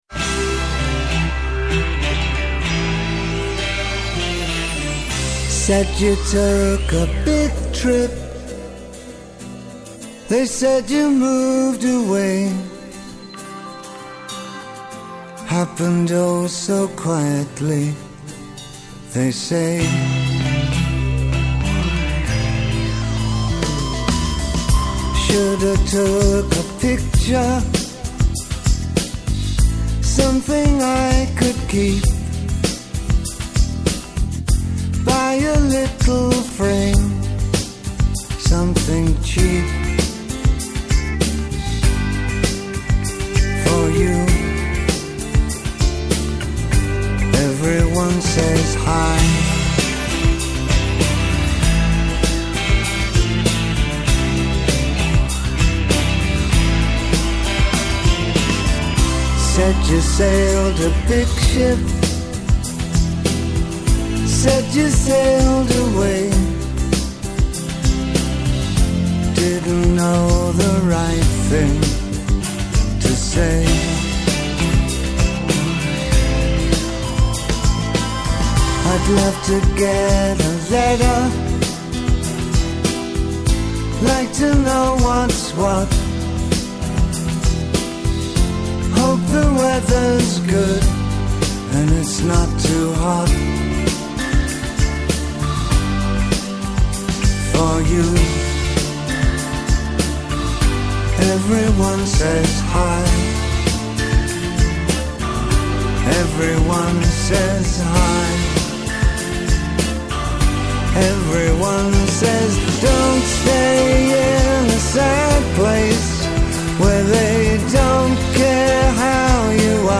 Καλεσμένος σήμερα ο Νίκος Παπαθανάσης, Αναπληρωτής Υπουργός Οικονομικών.
Συνεντεύξεις